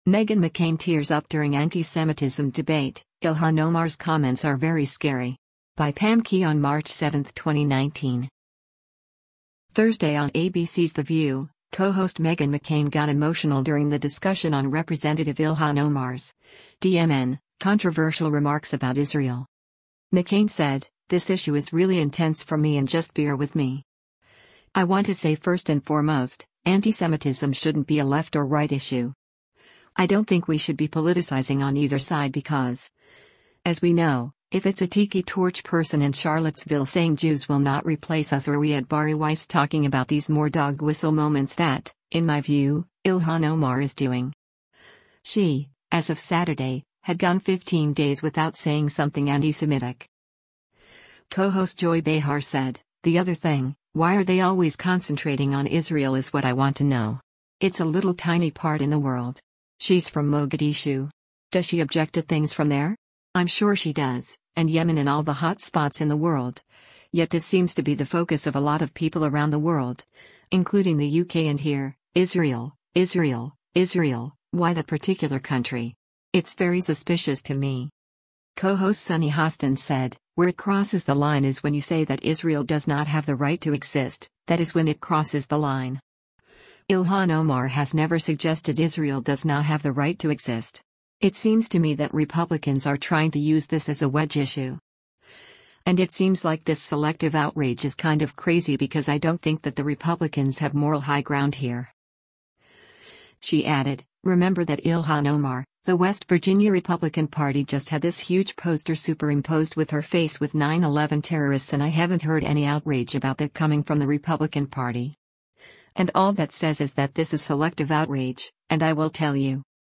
Meghan McCain Tears Up During Antisemitism Debate -- Ilhan Omar’s Comments Are ‘Very Scary’
Thursday on ABC’s “The View,” co-host Meghan McCain got emotional during the discussion on Rep. Ilhan Omar’s (D-MN) controversial remarks about Israel.